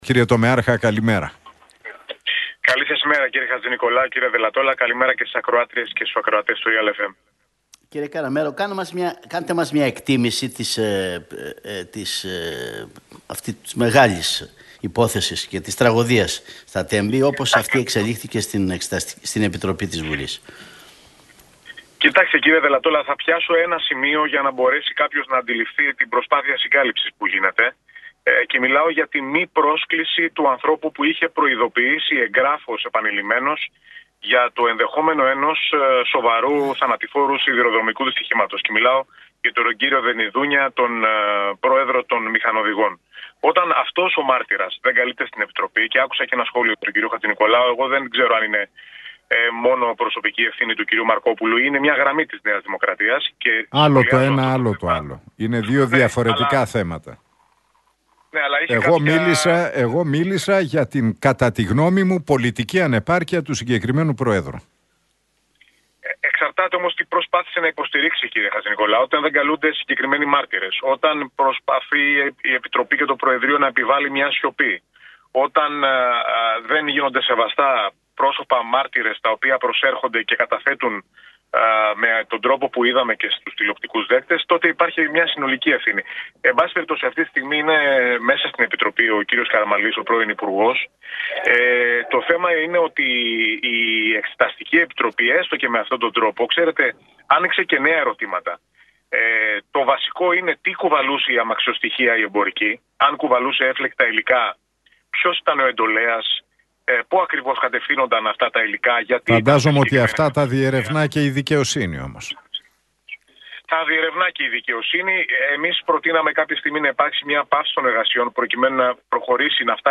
Για τα Τέμπη, την εξέλιξη της διαδικασίας στην Εξεταστική Επιτροπή αλλά και για το θέμα και τις αντιδράσεις που έχουν προκύψει με τον Πάνο Βλάχο μίλησε μεταξύ άλλων ο Τομεάρχης Ψηφιακής Πολιτικής του ΣΥΡΙΖΑ, Γιώργος Καραμέρος στον Realfm 97,8 και την εκπομπή του Νίκου Χατζηνικολάου με τον Αντώνη Δελλατόλα.